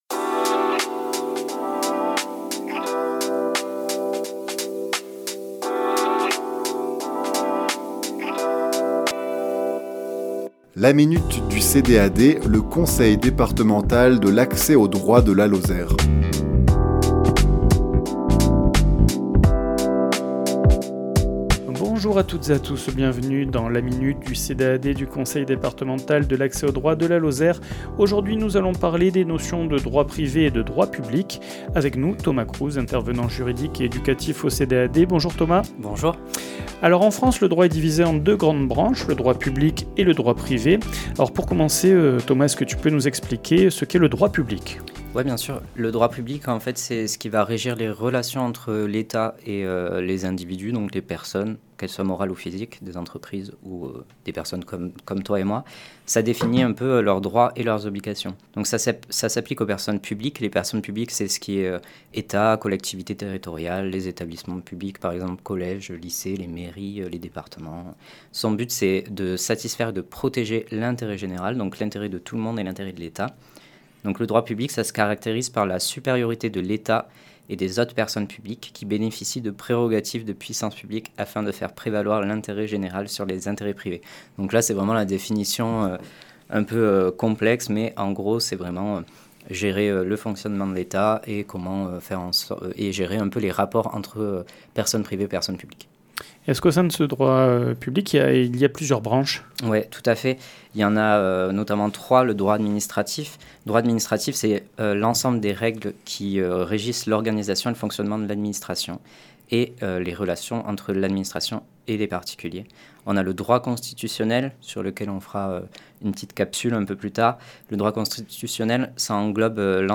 Chronique diffusée le lundi 2 décembre à 11h00 et 17h10